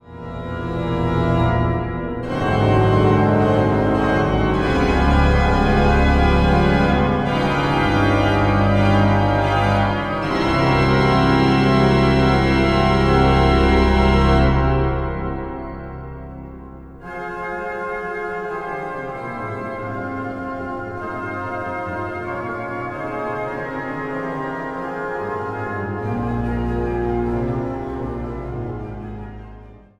orgels